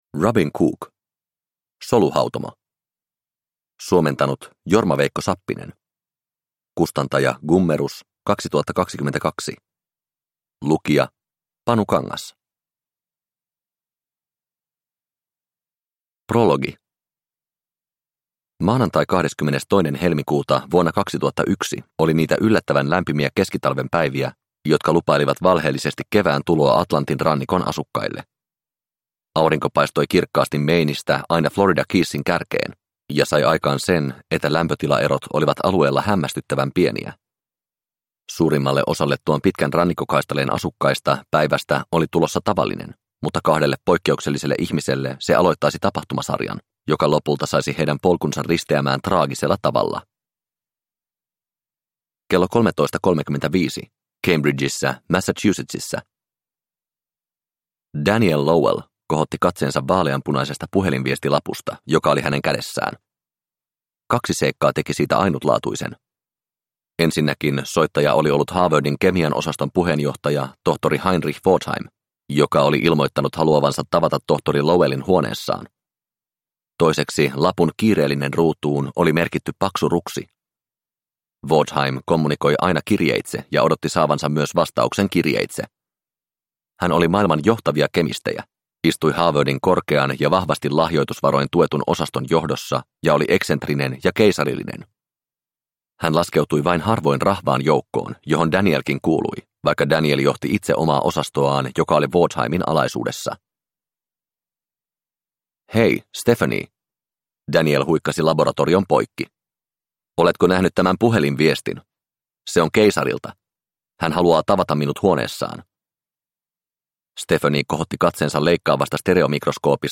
Soluhautomo – Ljudbok – Laddas ner